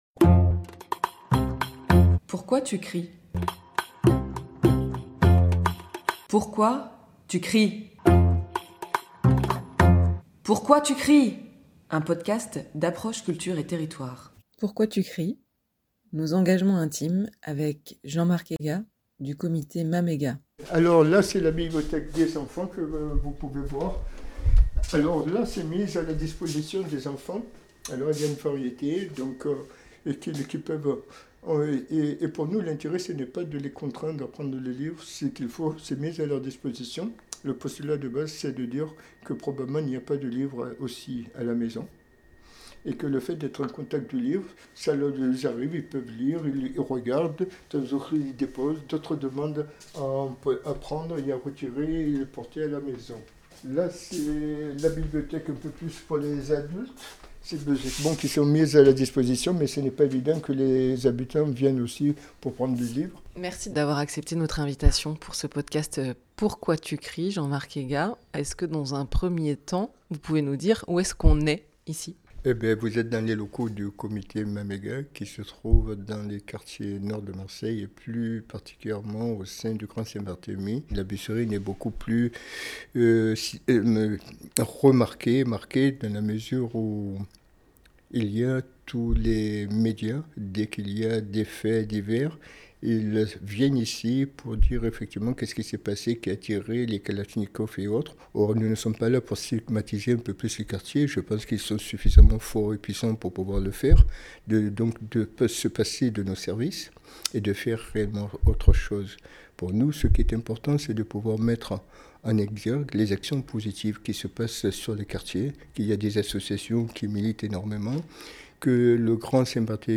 Extraits sonores : Surgissement – Théâtre du Centaure